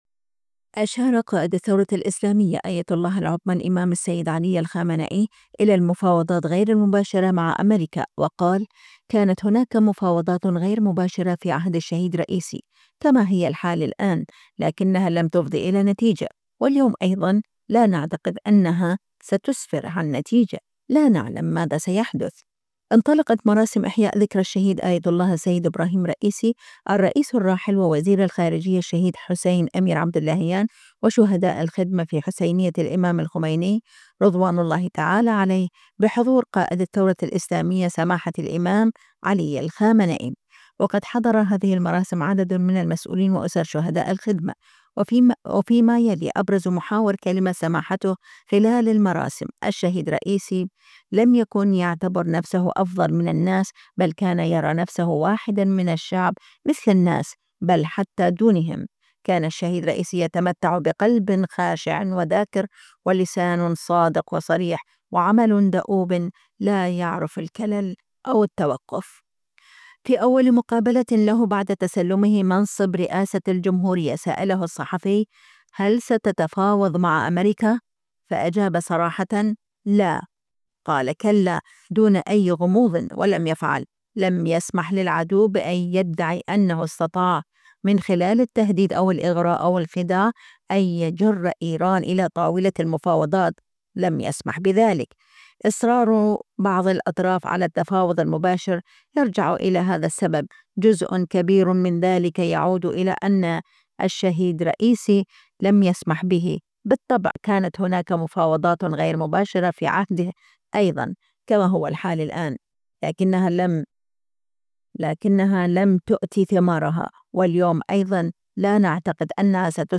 انطلقت مراسم إحياء ذكرى الشهيد آية الله السيد إبراهيم رئيسي الرئيس الراحل ووزير الخارجية الشهيد حسين اميرعبداللهيان و"شهداء الخدمة" في حسينية الإمام الخميني (رض) بحضور قائد الثورة الإسلامية، سماحة الامام علي الخامنئي.
وقد حضر هذه المراسم عدد من المسؤولين وأُسر شهداء الخدمة.